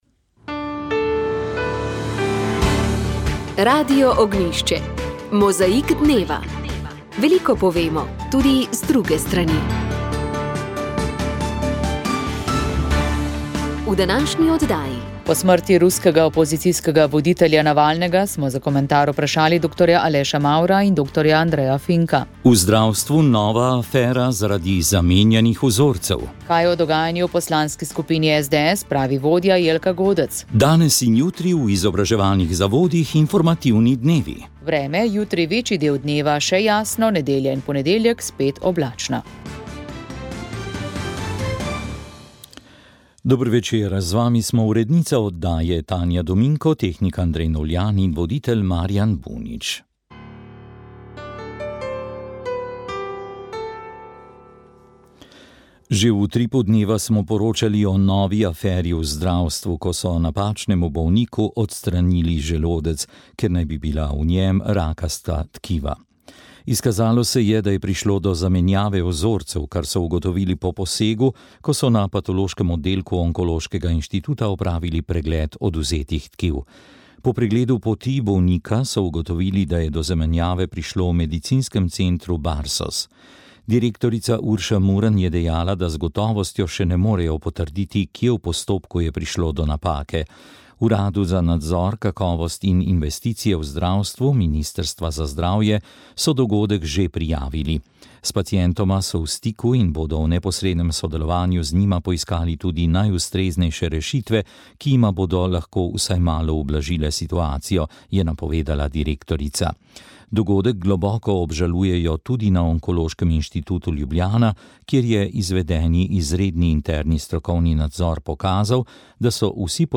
Ob teh in vprašanjih poslušalcev smo iskali odgovore pri karierni svetovalki ter zakonski in družinski terapevtki